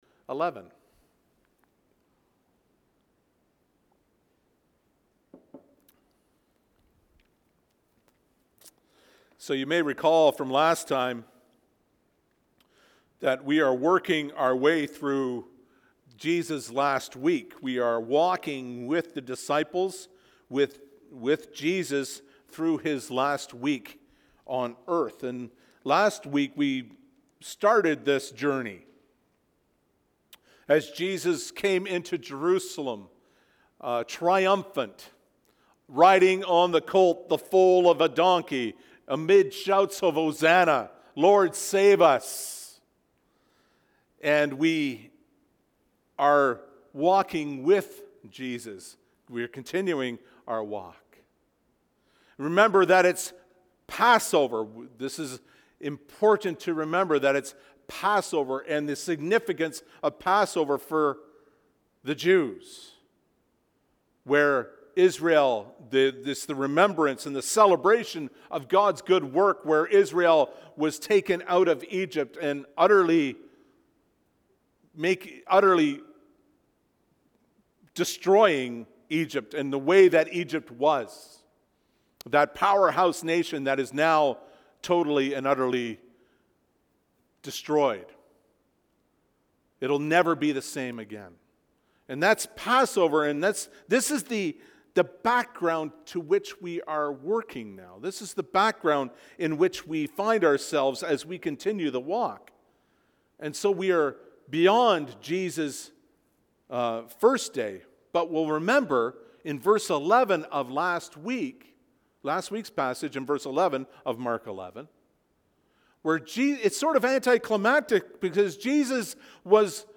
Audio of Sermons